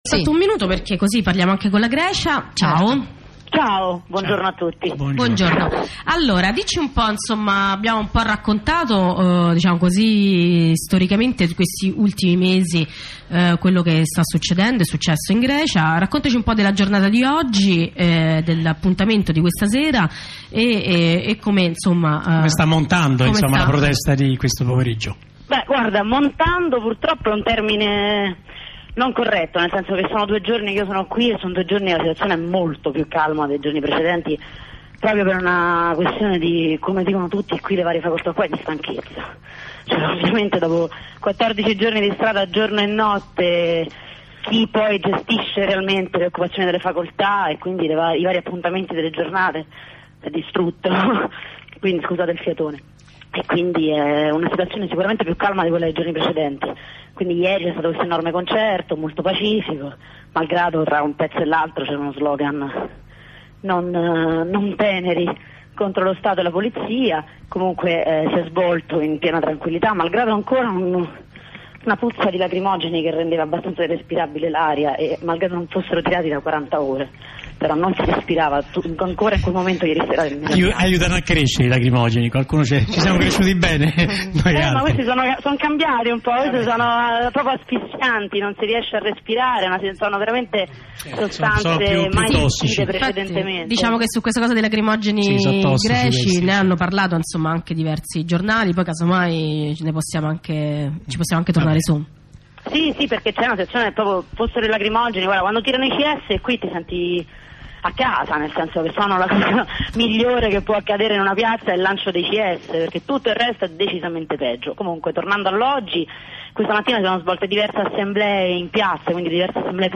Corrispondenza con Radio Onda Rossa